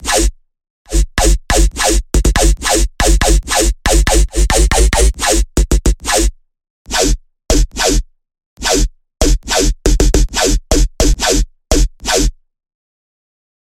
标签： 140 bpm Dubstep Loops Bass Wobble Loops 2.31 MB wav Key : Unknown
声道立体声